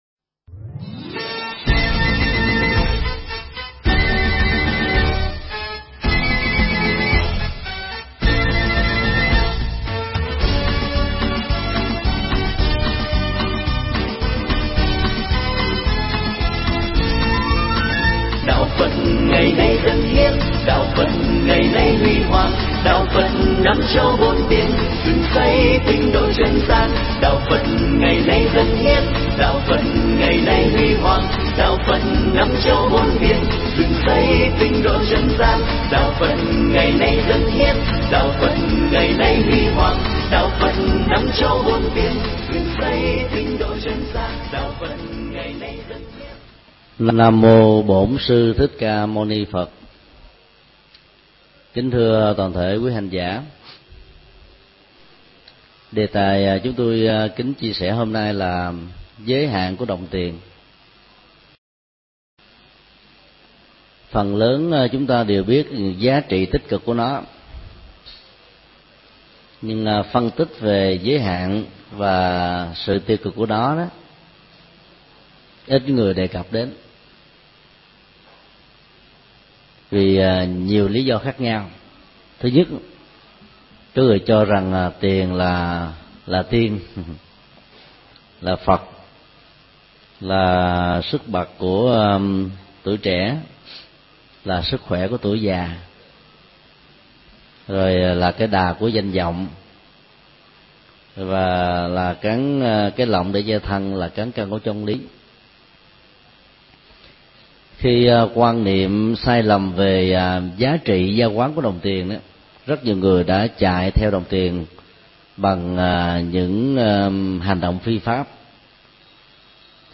Pháp thoại Giới hạn của đồng tiền
giảng tại Chùa Giác Ngộ